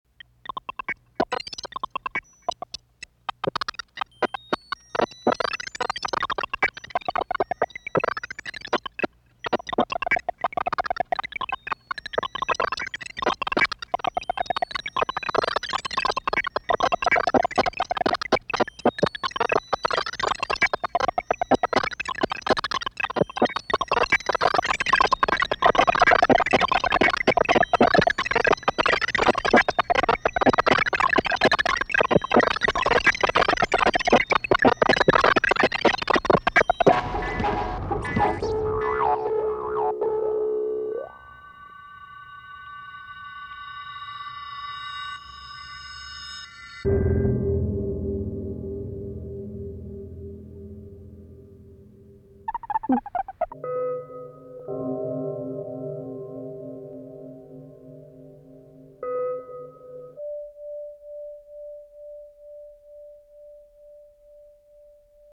musique concrète